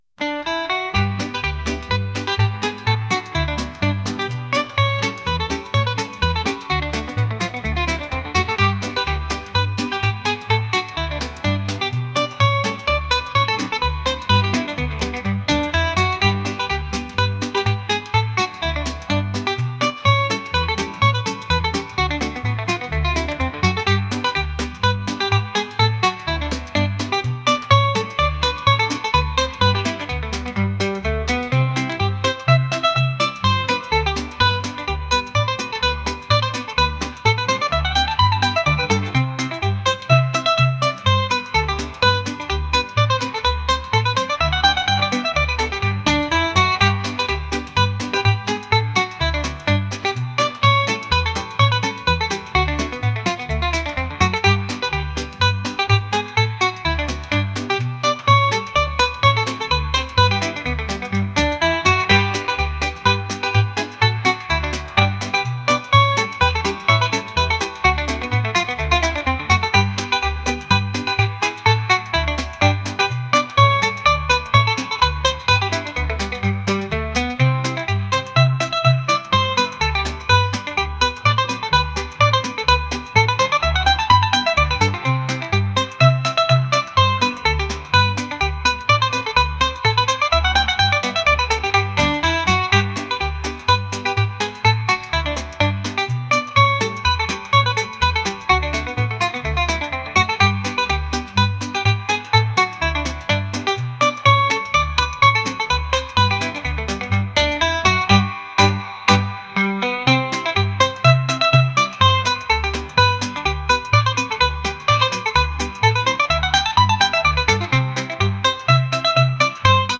pop | reggae | lofi & chill beats